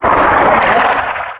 concmissilefire7.wav